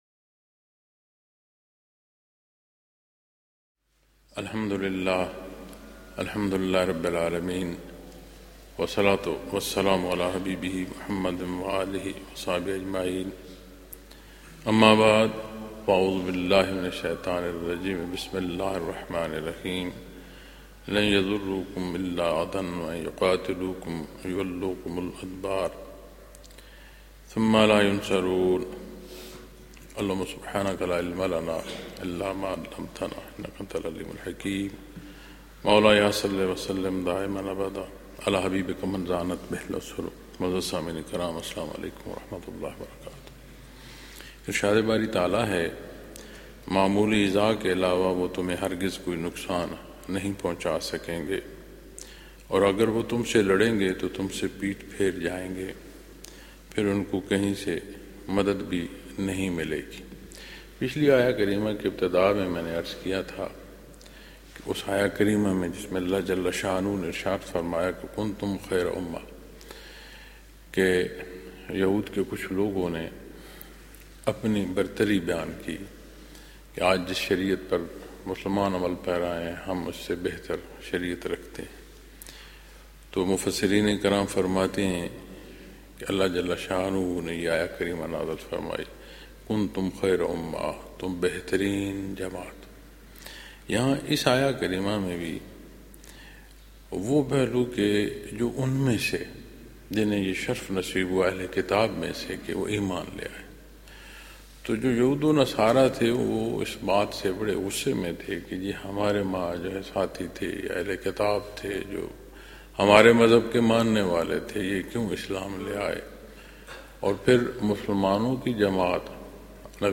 Lectures in Munara, Chakwal, Pakistan